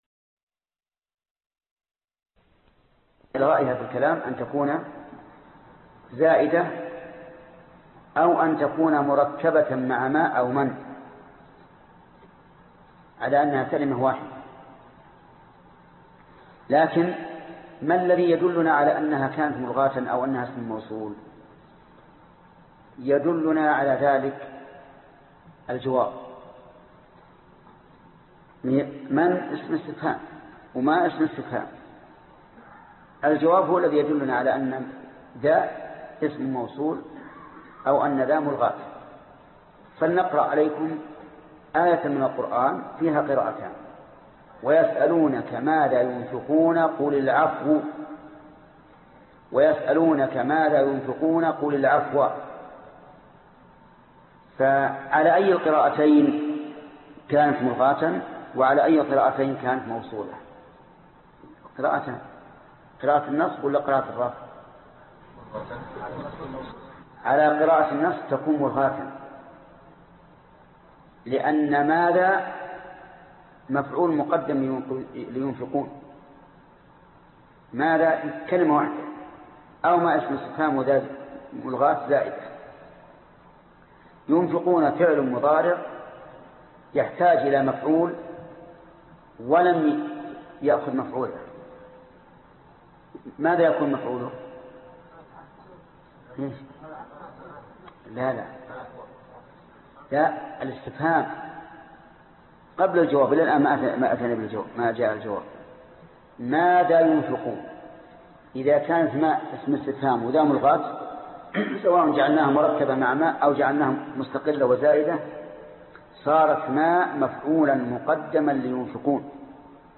الدرس 70 ( شرح الفية بن مالك ) - فضيلة الشيخ محمد بن صالح العثيمين رحمه الله